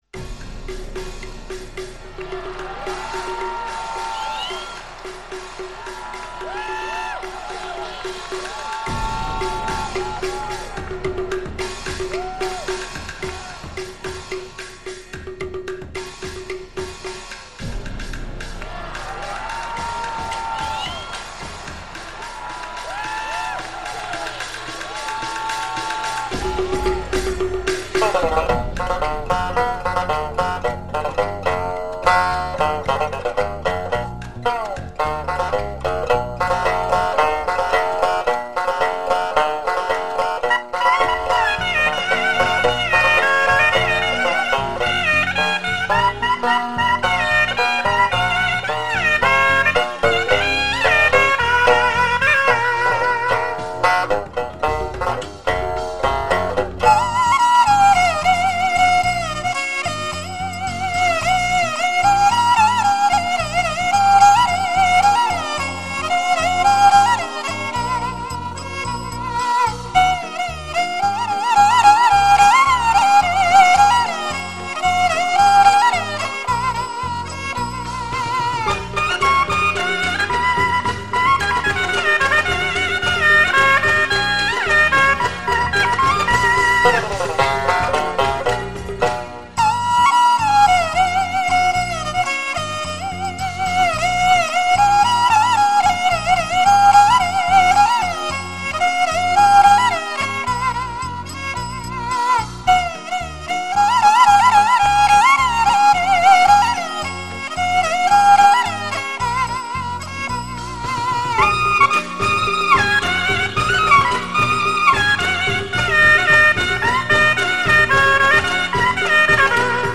【所属类别】音乐 国乐/民族
辽宁民间音乐的基础是关内音乐，同时融合了满、朝鲜、蒙古等少数民族音乐的特点。
东北沃野万里、物产丰盛，这块土地上蘊育的民乐，也显得丰腴、乐观、健朗、泼辣。
发烧音效，堪称民乐天碟。